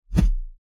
kick_short_whoosh_12.wav